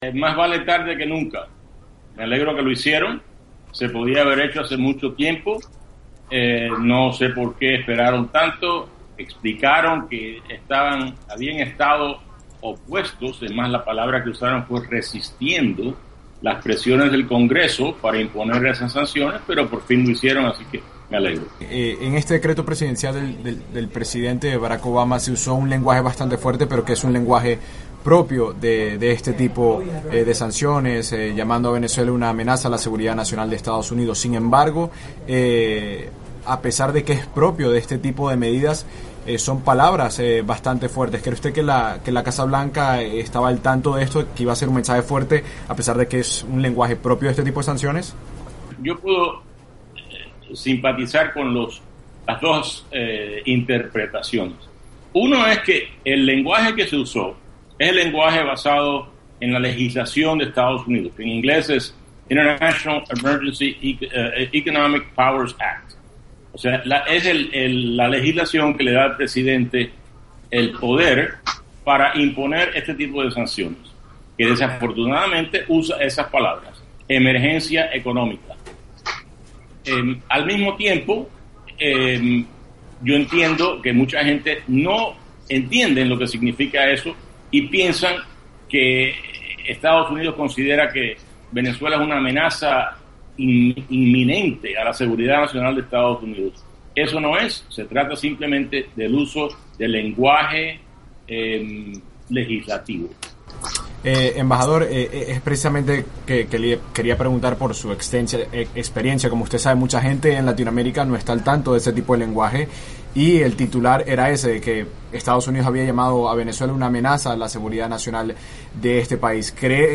Entrevista con Otto Reich